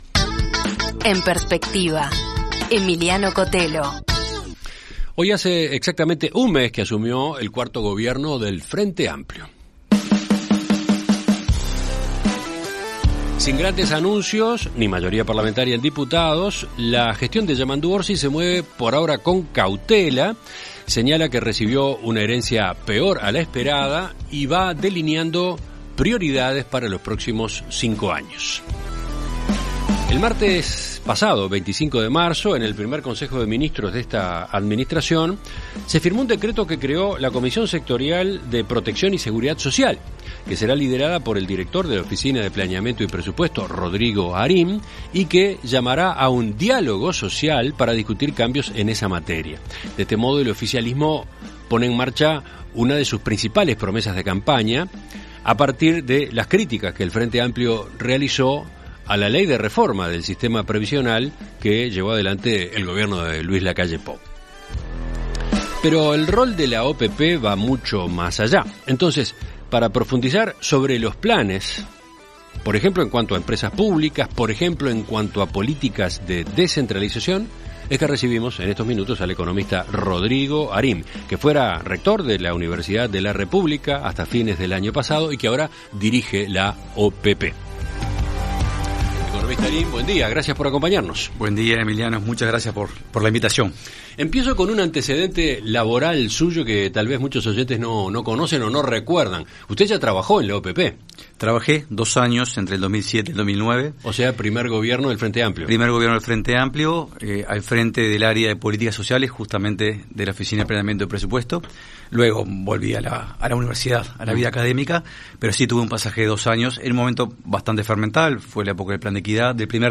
Entrevista Central